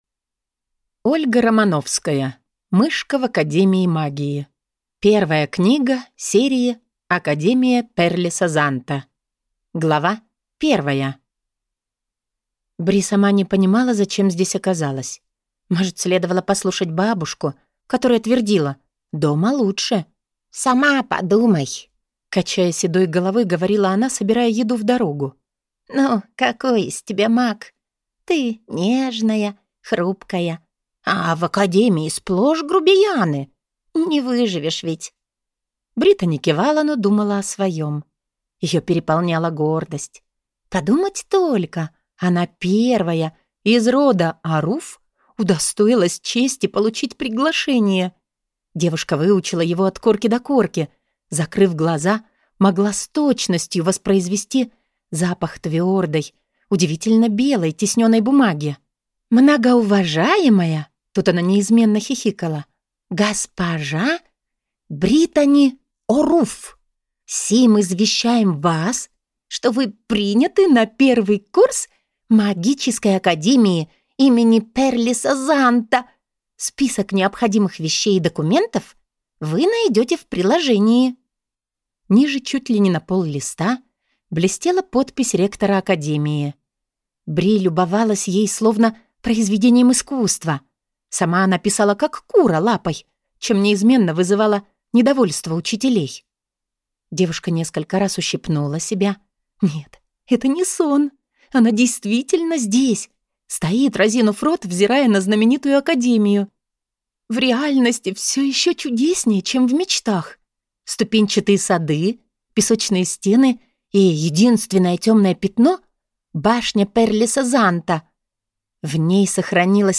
Аудиокнига Мышка в академии магии | Библиотека аудиокниг